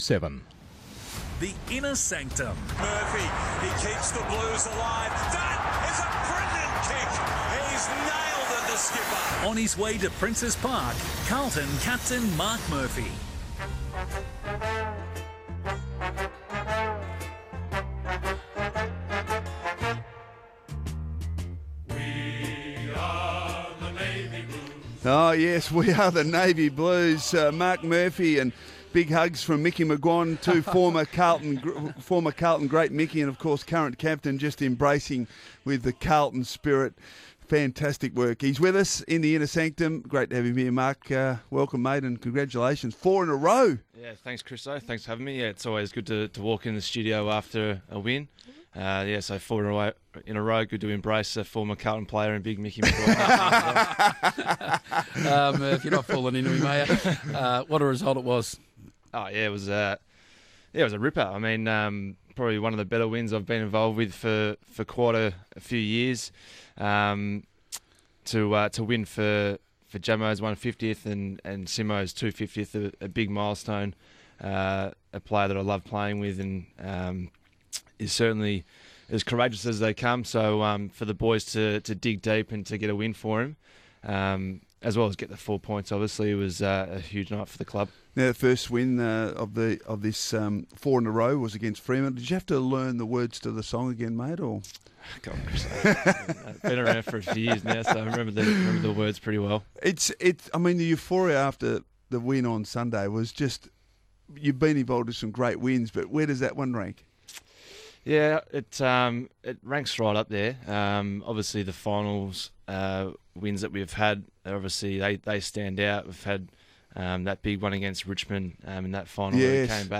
Carlton captain Marc Murphy speaks at length on Radio Sport National's 'Inner Sanctum' after the Blues' win over Port Adelaide.